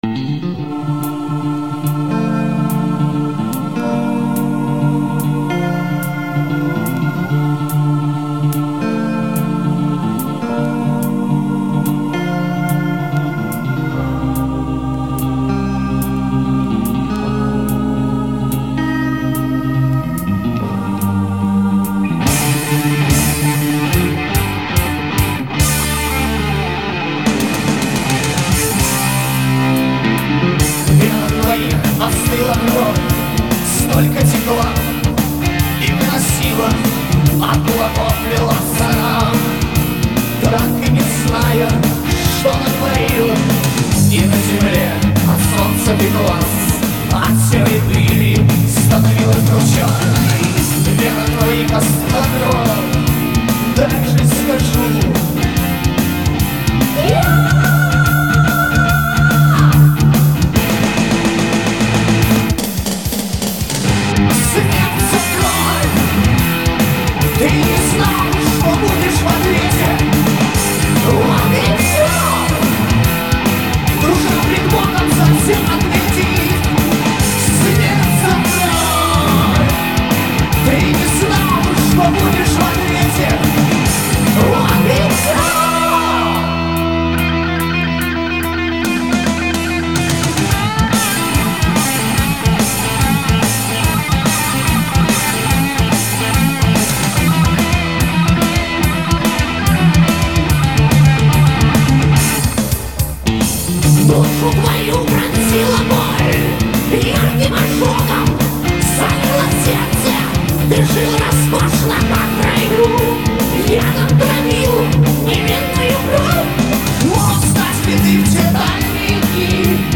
Играет в стилях: Hard Rock , Heavy Metal